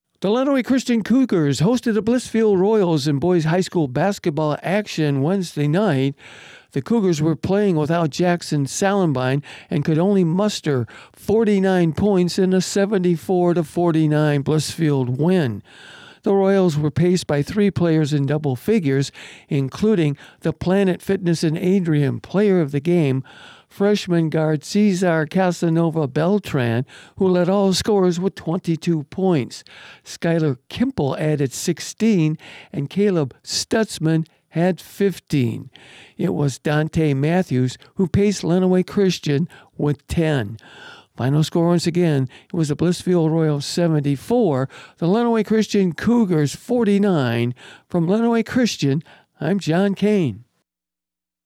called the game for 96.5fm The Cave and Lenawee TV…